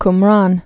(km-rän)